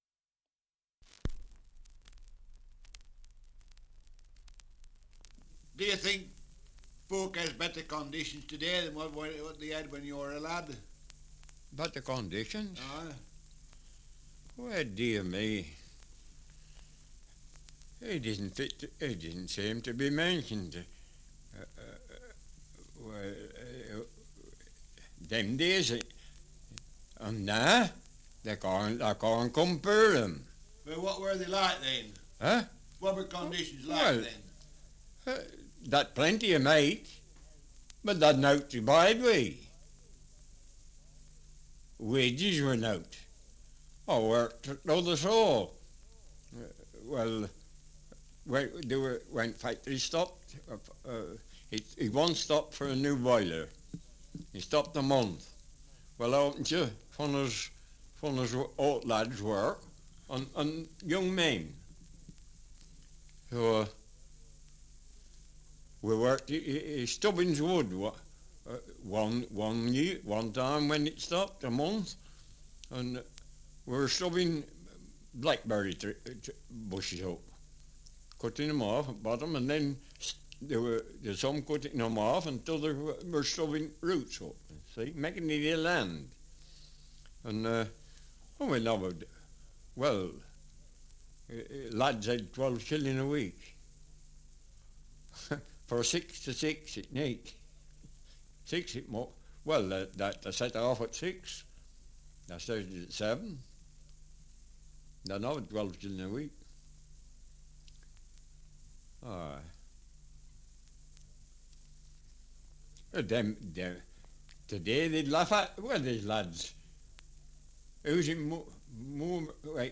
Survey of English Dialects recording in Ribchester, Lancashire
78 r.p.m., cellulose nitrate on aluminium